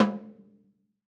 Snare Zion 3.wav